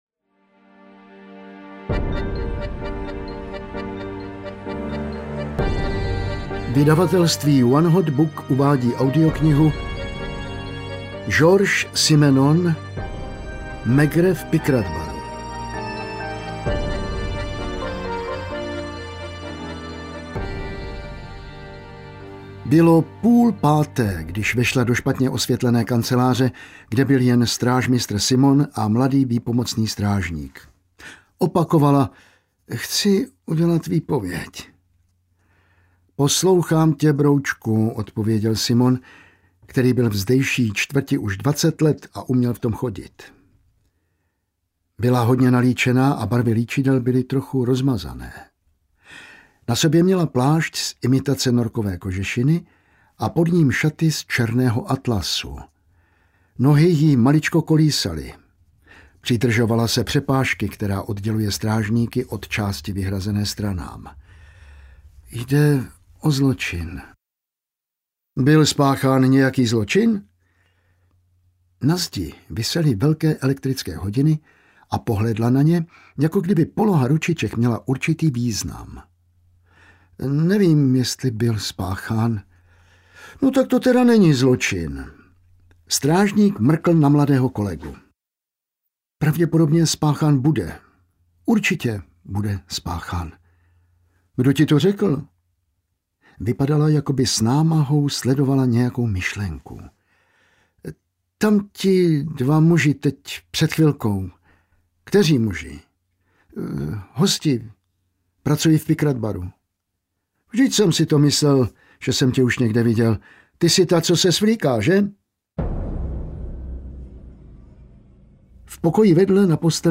Maigret v Picratt Baru audiokniha
Ukázka z knihy